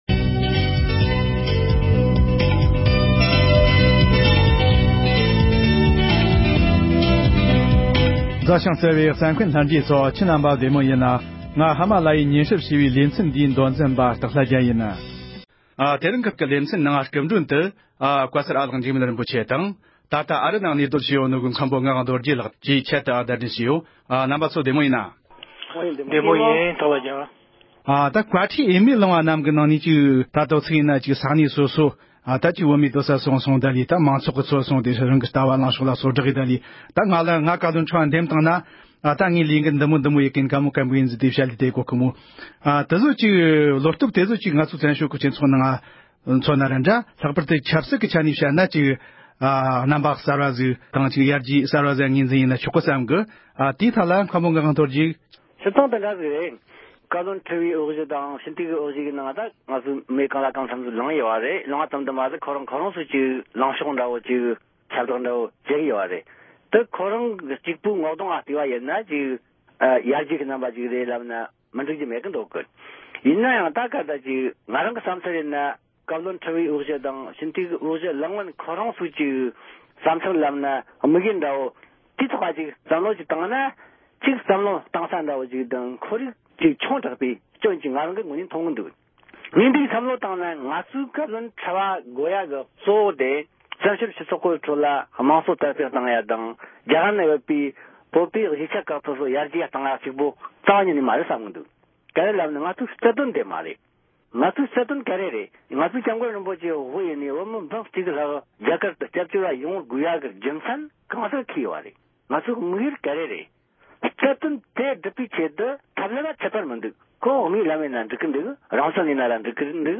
སྐབས་གསུམ་པའི་བཀའ་བློན་ཁྲི་པའི་འོས་འདེམས་དང་འབྲེལ་བའི་ཐད་མི་སྣ་ཁག་དང་ལྷན་དུ་བགྲོ་གླེང་།
སྒྲ་ལྡན་གསར་འགྱུར།